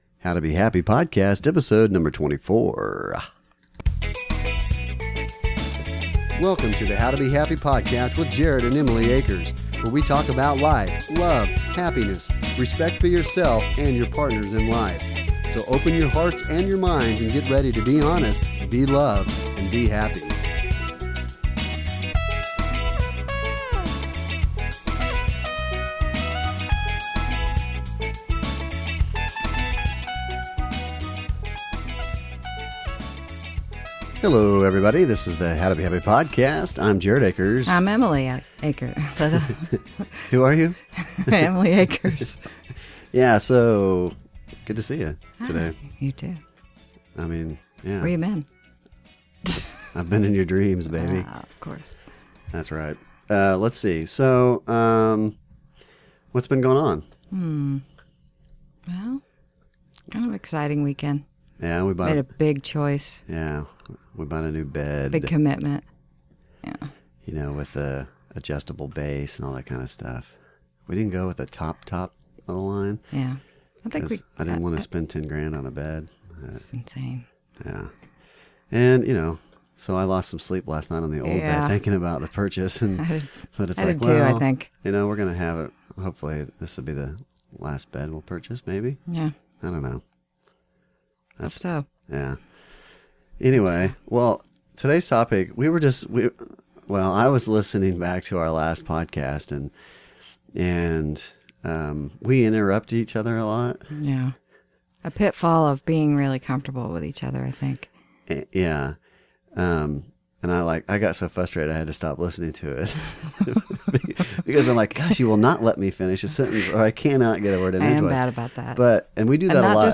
We talk over each other on occasion, especially if we’re sharing something about ourselves with others.